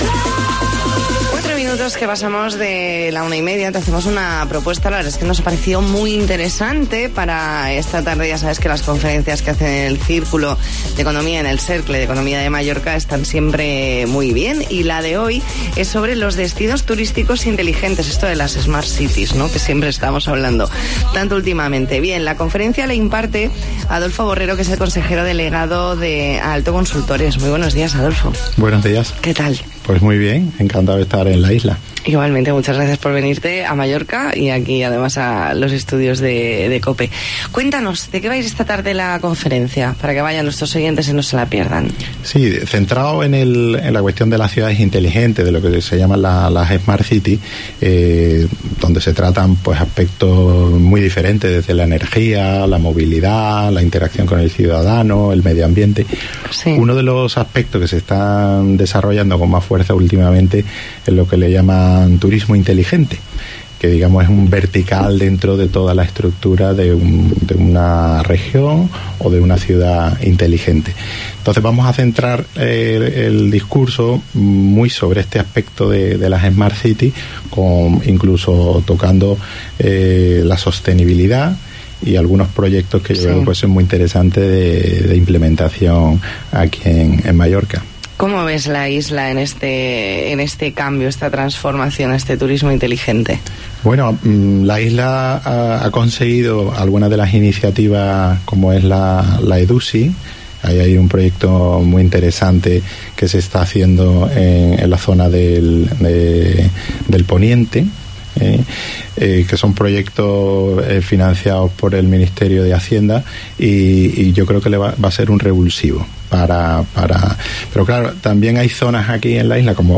Entrevista en La Mañana en COPE Más Mallorca, jueves 16 de mayo de 2019.